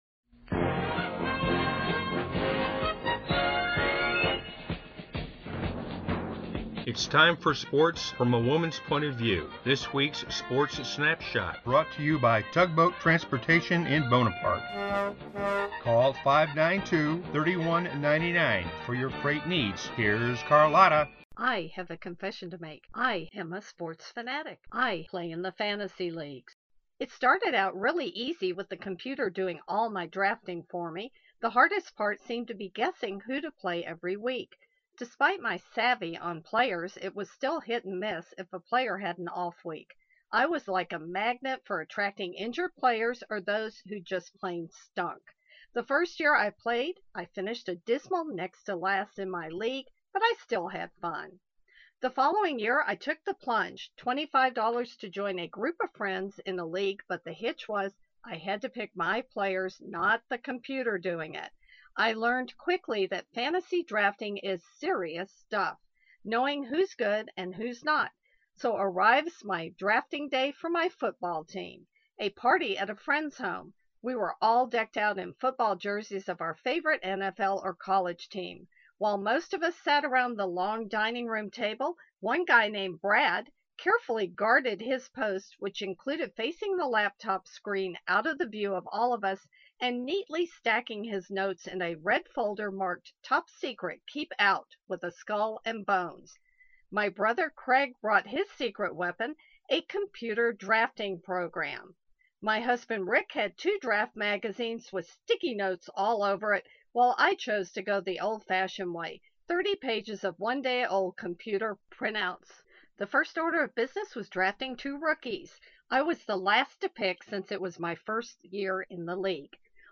Here’s a recent Sports Snapshot: